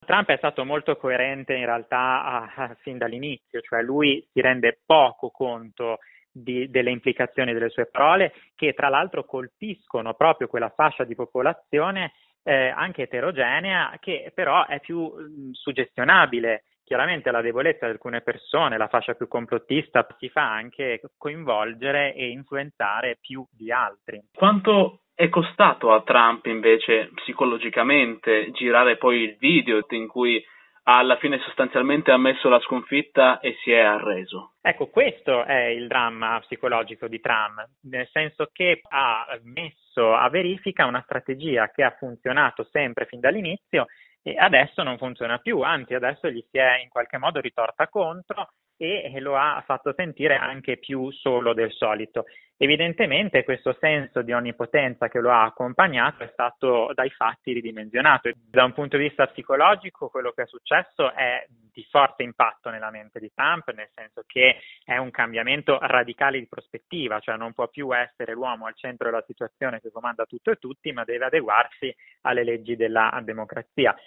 Sulla psicologia di Trump abbiamo intervistato lo psicologo e psicoterapeuta